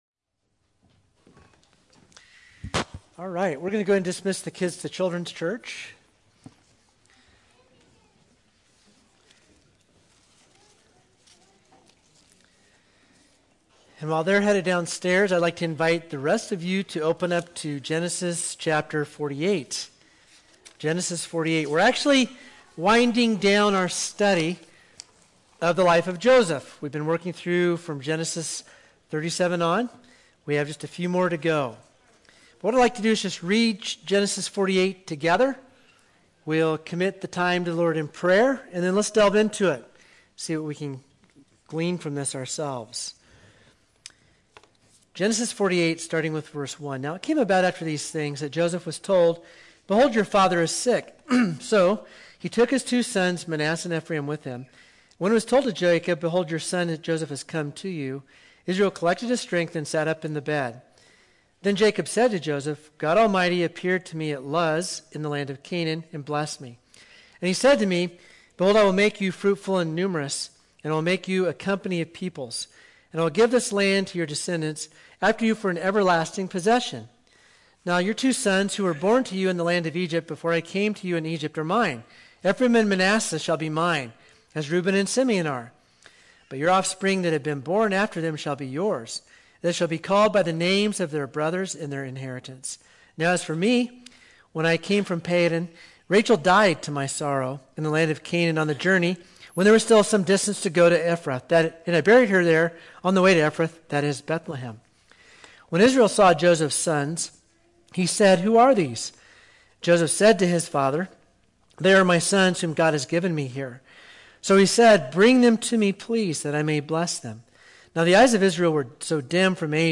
5_30-21-sermon.mp3